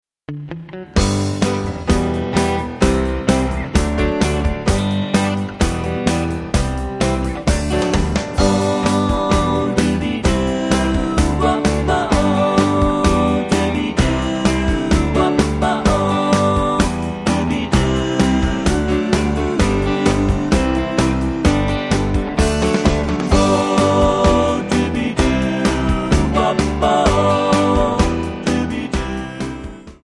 Backing track files: All (9793)
Buy With Backing Vocals.